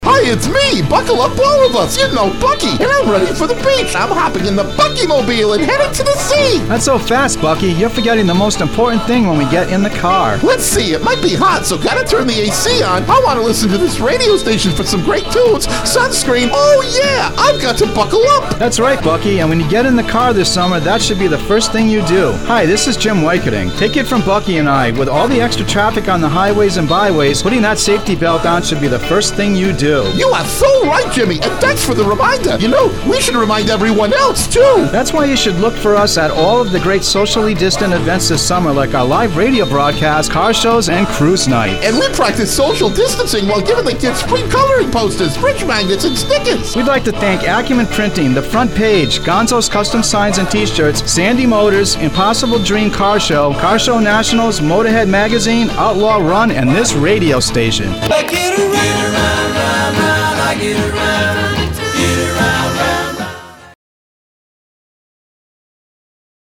spot-bucky.mp3